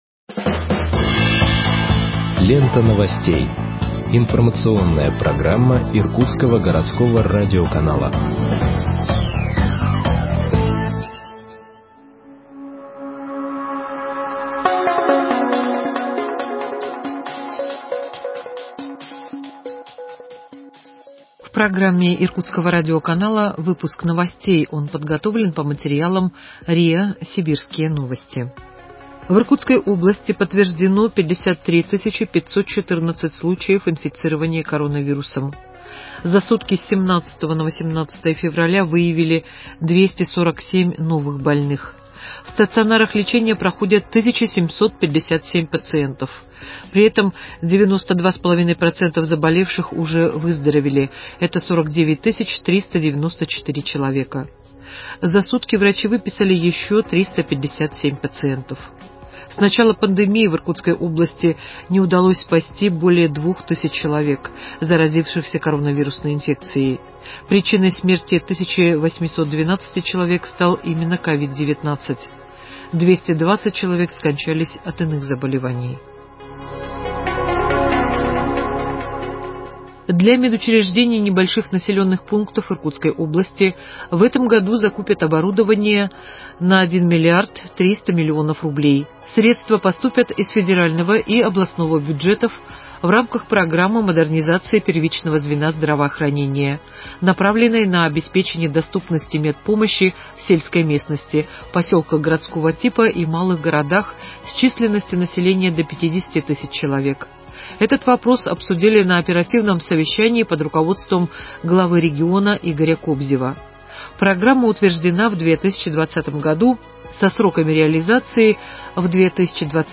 Выпуск новостей в подкастах газеты Иркутск от 19.02.2021 № 1